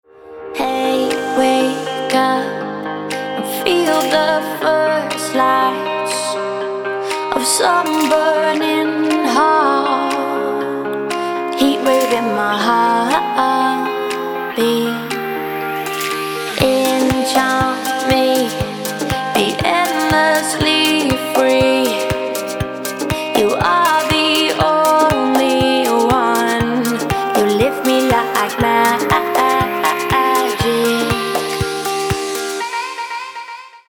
• Качество: 256, Stereo
поп
женский вокал
deep house
красивый женский голос
Melodic
vocal